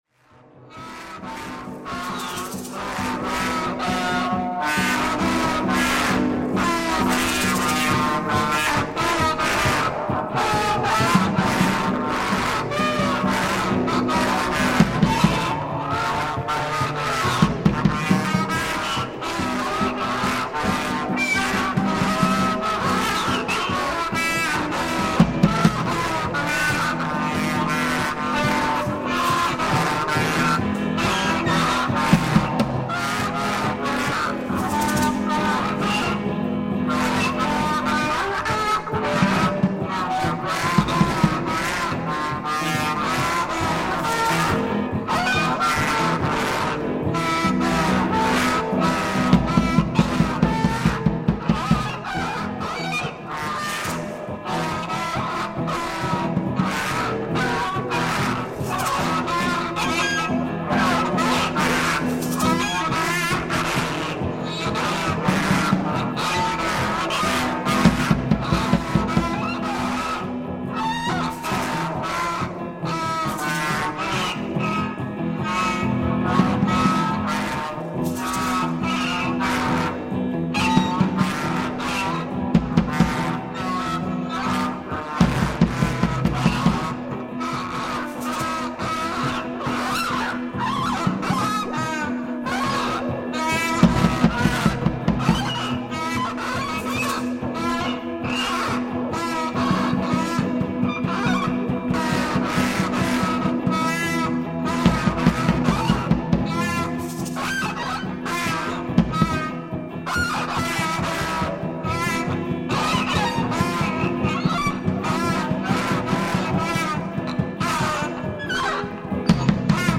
Again, without rehearsals or repeats.
All three are non-educated and non-professional musicians.